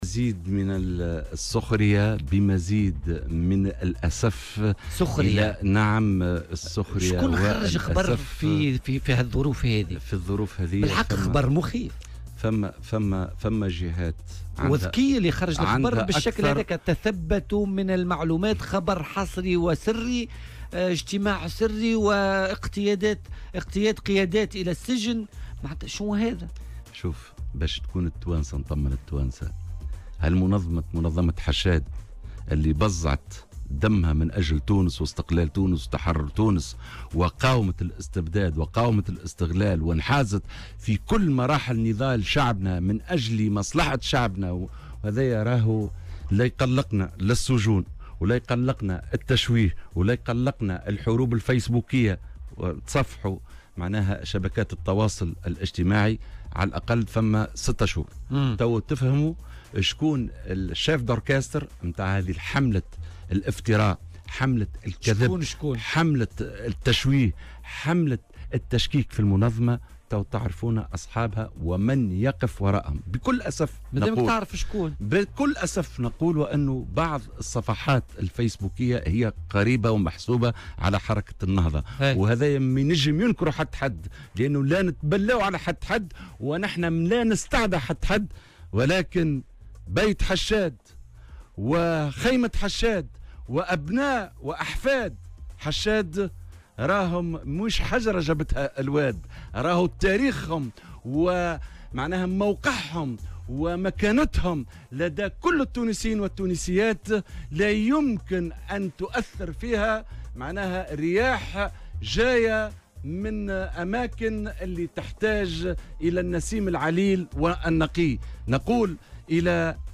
وأضاف في مداخلة له اليوم في برنامج "بوليتيكا" أن الأخبار التي يتم ترويجها بخصوص إحالة عدد من قيادات الاتحاد على القضاء على خلفية شبهات فساد، لا أساس لها من الصحة ومجرّد حملات تشويه و افتراء تقف ورائها صفحات محسوبة على حركة النهضة.